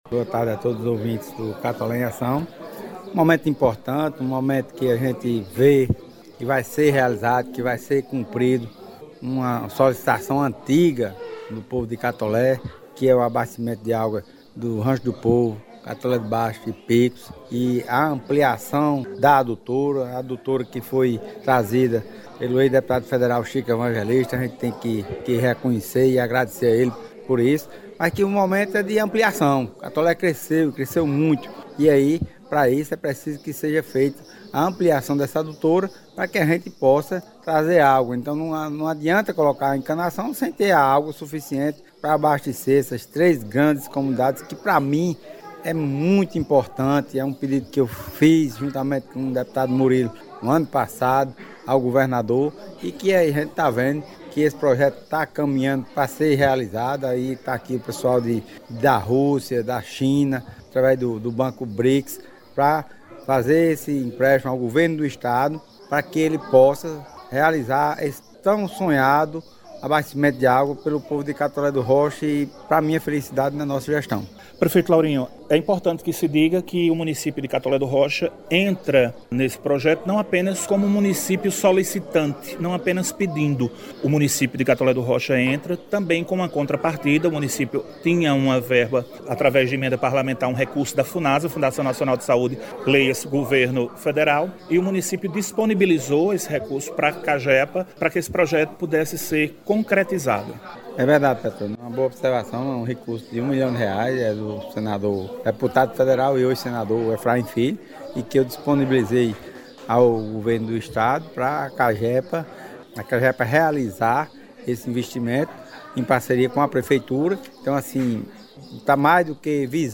Foi ao ar na sexta-feira, do dia 16 de junho do ano de 2023, uma entrevista ao programa Catolé em Ação, trazendo como destaques a participação do prefeito de Catolé do Rocha, Laurinho Maia, e do presidente da CAGEPA (Companhia de Água e Esgotos da Paraíba), Marcus Vinícius.
Acompanhe com muita atenção trechos da entrevista do Prefeito Laurinho Maia